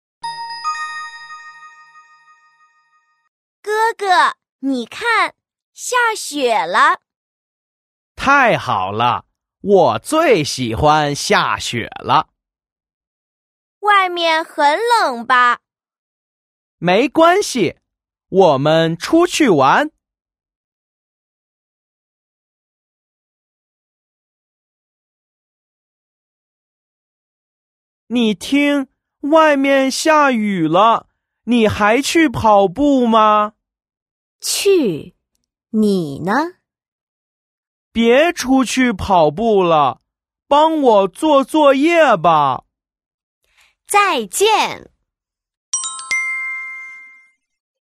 Bài học có 2 đoạn hội thoại quan trọng.